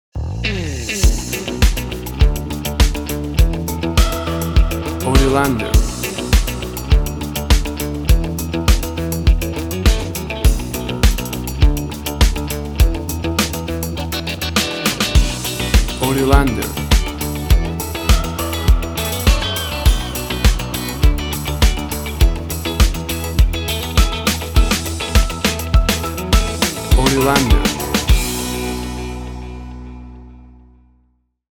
WAV Sample Rate: 24-Bit stereo, 44.1 kHz
Tempo (BPM): 102